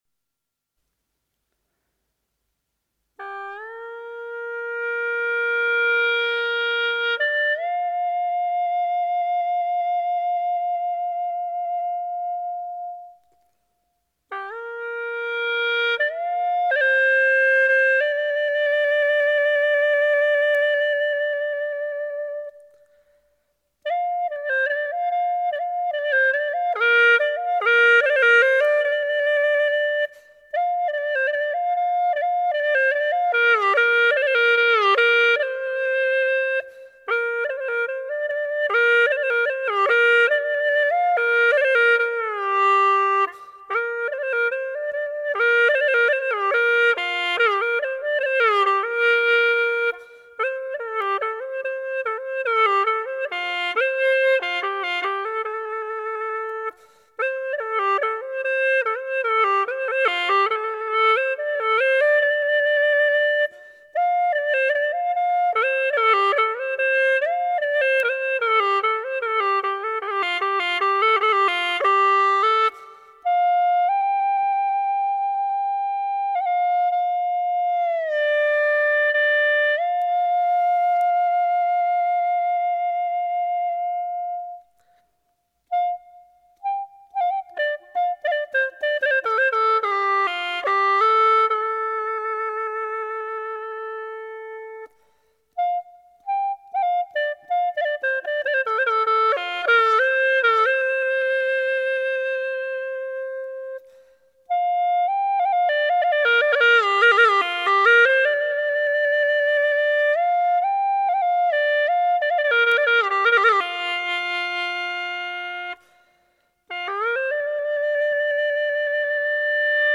调式 : D 曲类 : 独奏